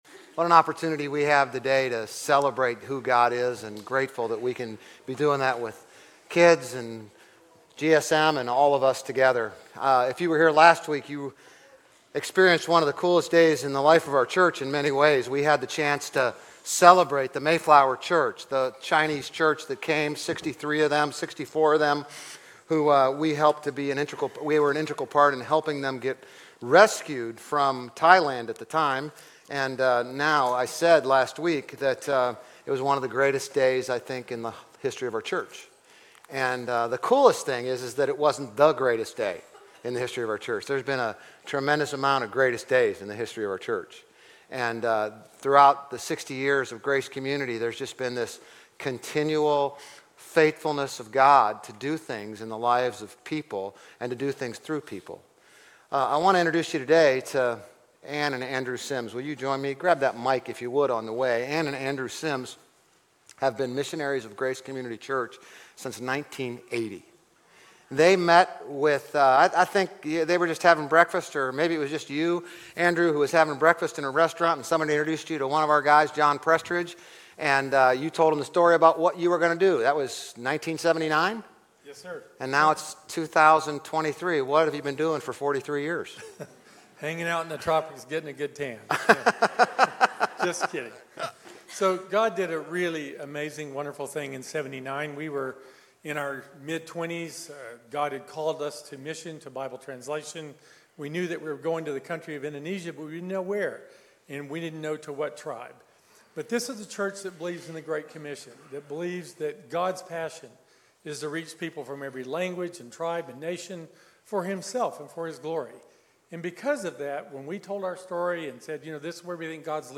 GCC-OJ-August-20-Sermon.mp3